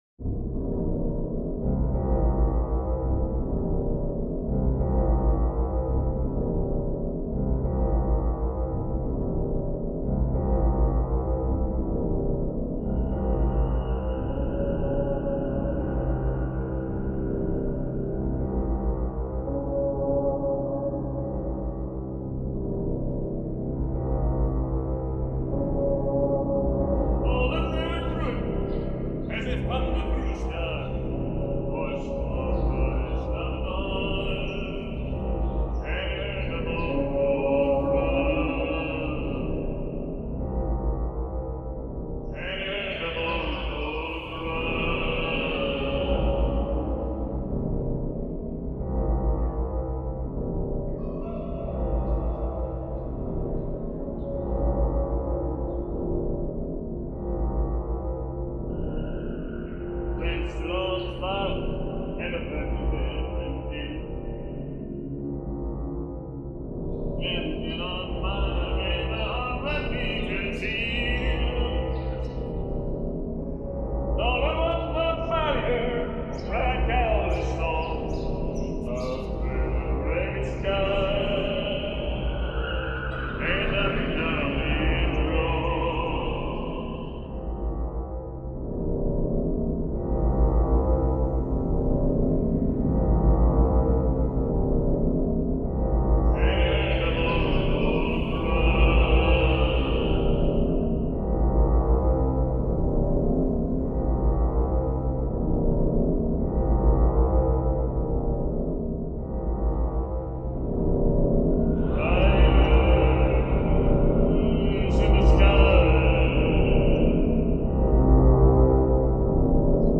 Folk songs in Bremerhaven harbour reimagined by Citrus Garden